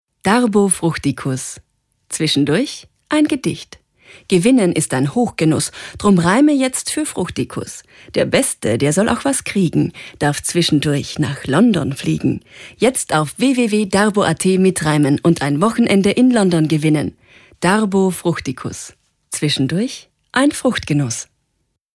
Sprecherin Stimme: natürlich, freundlich, sinnlich Hörbuch & App für Kinder
Sprechprobe: Werbung (Muttersprache):